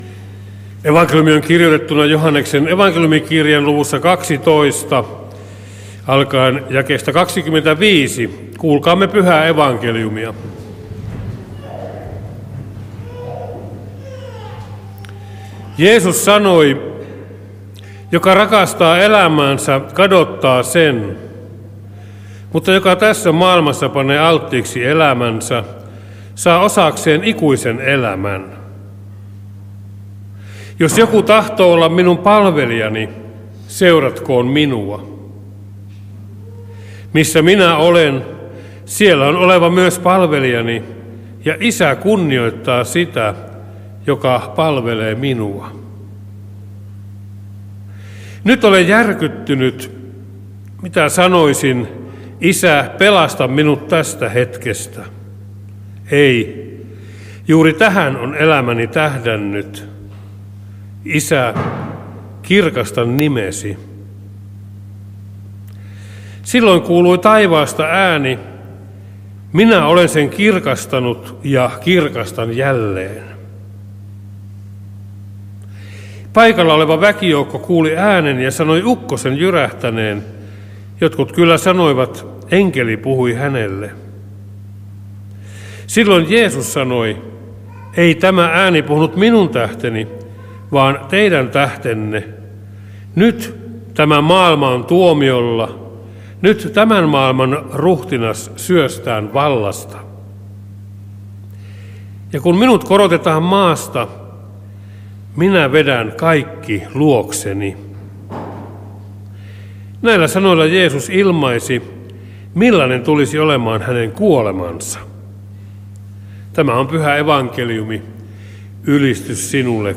saarna Lahdessa laskiaissunnuntaina